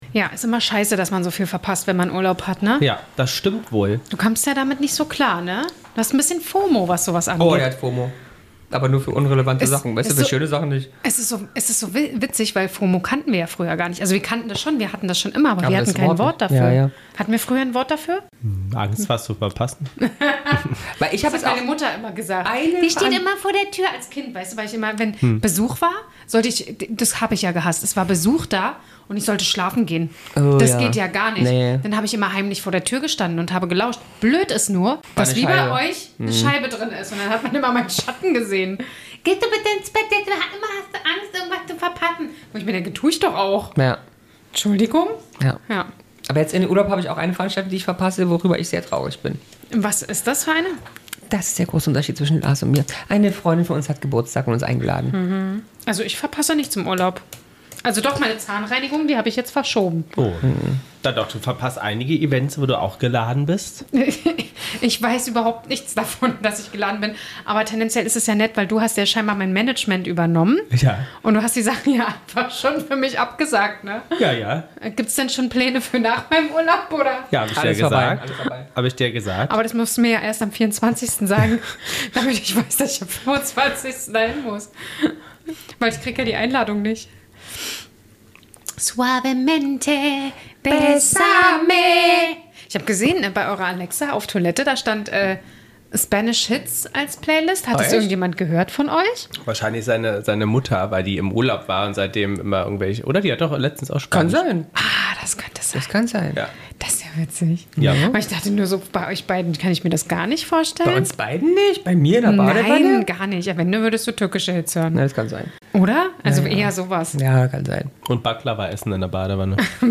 Mit dabei: unser nicht-weihnachtlicher Song mit Rap-Elementen und osteuropäischem Akzent.